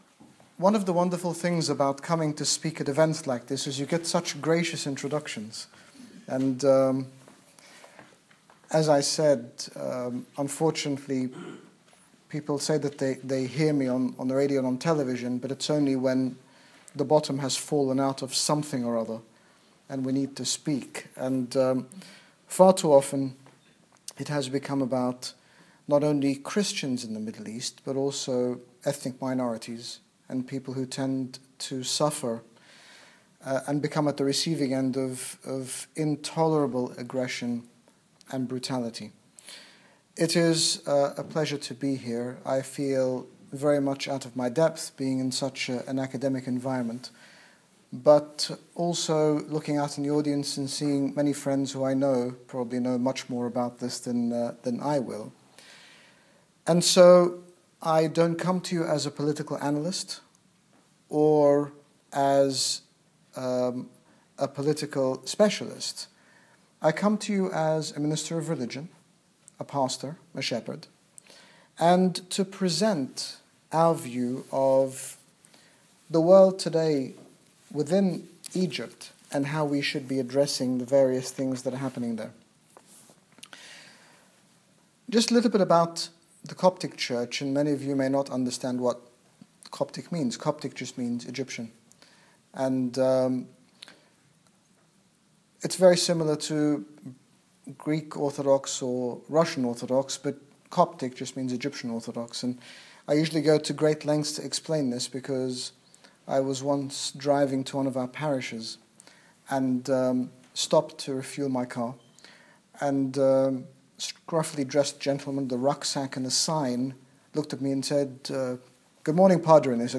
His Grace Bishop Angaelos, General Bishop of the Coptic Orthodox Church in the United Kingdom gave a keynote address in Pembroke College, University of Oxford on religion vs. politics in Egypt.